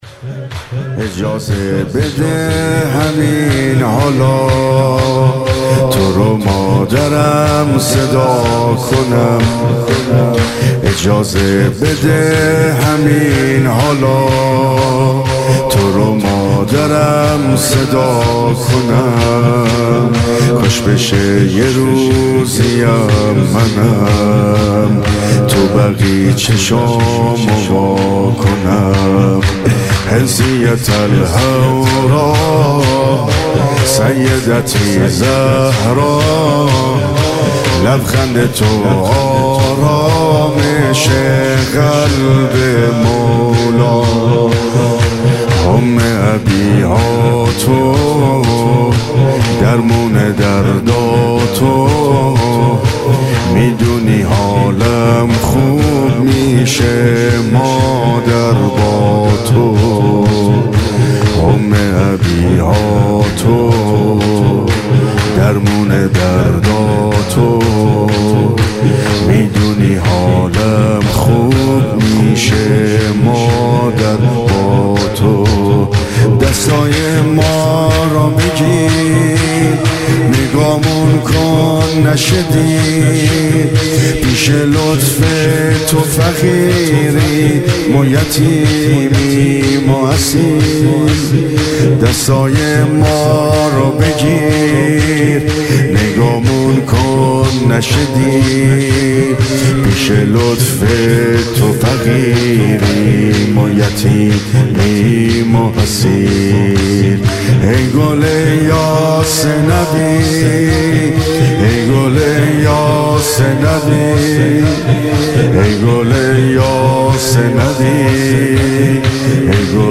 مذهبی